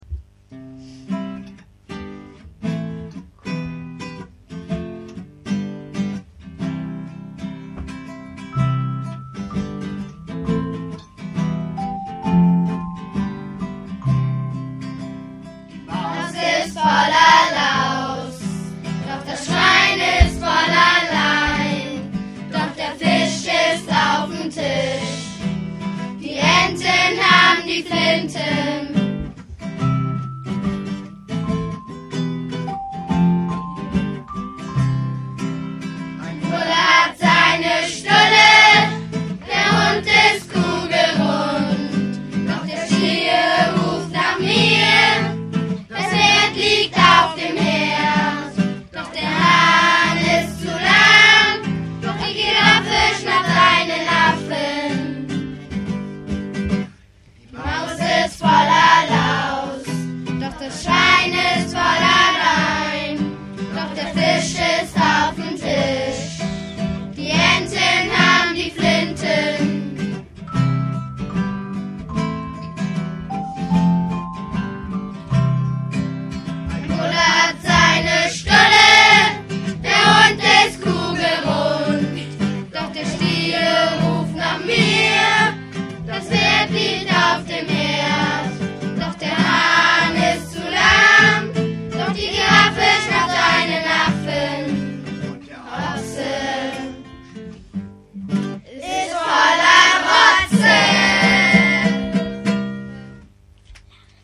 Jetzt wurde das 370 Seiten starke Werk „Trecker, lila Kuh & Co.“ im Emsteker Rathaus präsentiert:
Voller Begeisterung sangen die Kinder das selbst vertonte „Gedicht der Tiere“ von Ralf Lübbehüsen.
klasse4a-und-4b-lied-gesamt.mp3